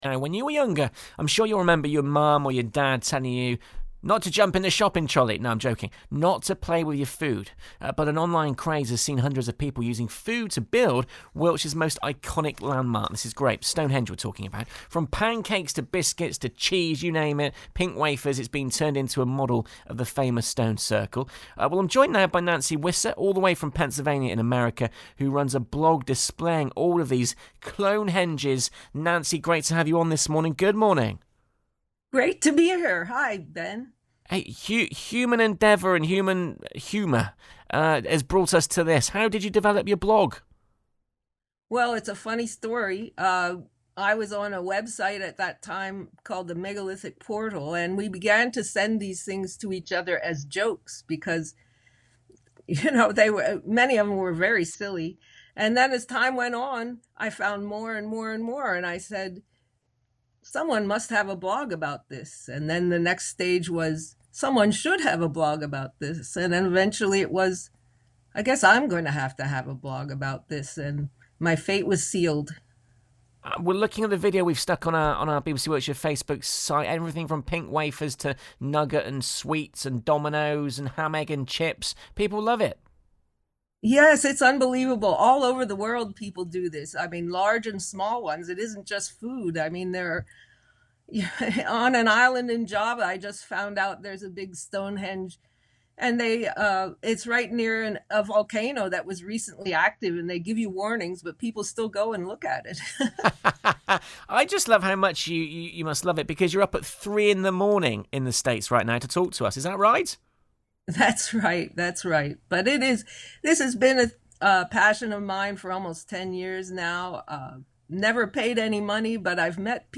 Clonehenge Interviewed on BBC Wiltshire’s Breakfast Show!
Here’s the interview: